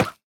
Minecraft Version Minecraft Version 1.21.4 Latest Release | Latest Snapshot 1.21.4 / assets / minecraft / sounds / mob / armadillo / hurt_reduced3.ogg Compare With Compare With Latest Release | Latest Snapshot
hurt_reduced3.ogg